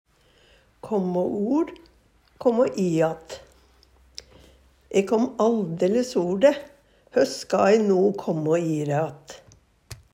kåmmå or-kåmmå i att - Numedalsmål (en-US)